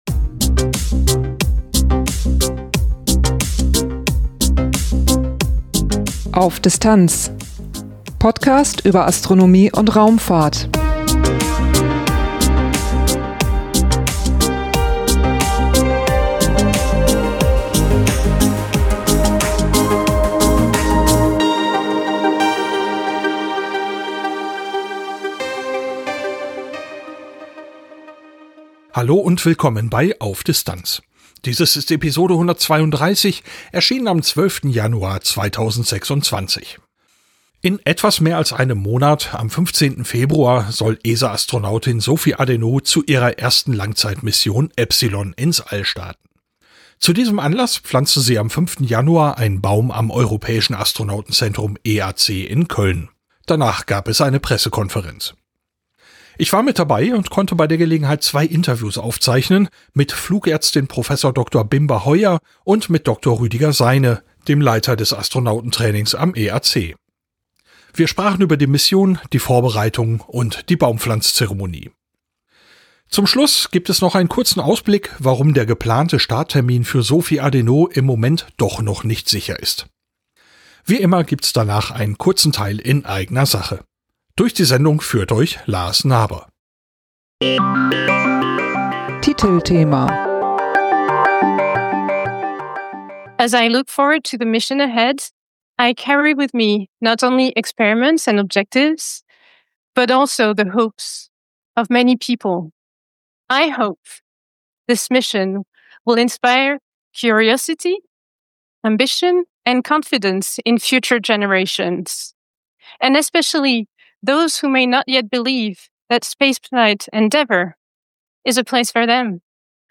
Auf Distanz 0132: Sophie Adenot und die Mission εpsilon Auf Distanz - Podcast über Astronomie und Raumfahrt Download Am 15.